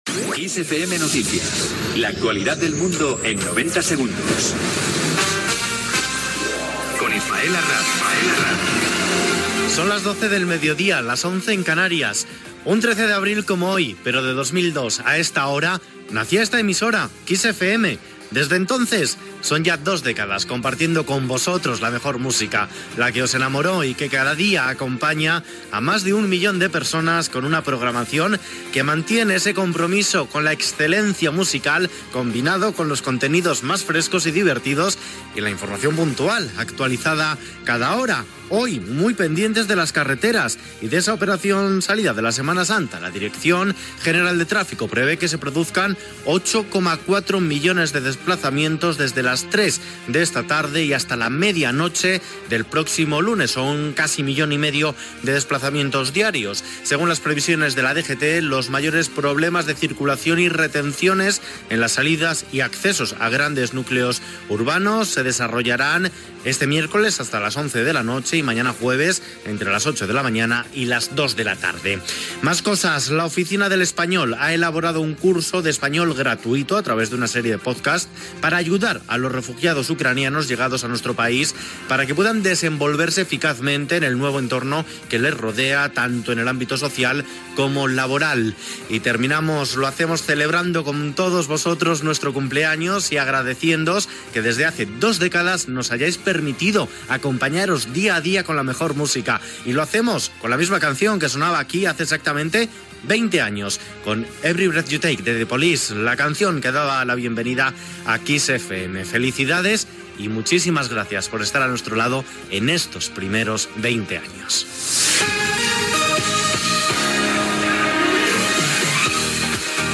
Careta del programa, informació: 20 anys de la inauguració de Kiss FM, vacances de Setmana Santa, cursos d'espanyol per a ucrïnesos, indicatiu dels 20 anys, tema musical
Informatiu